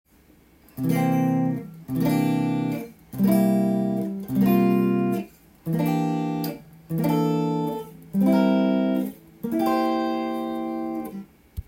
Cミクソリディン系テンションコード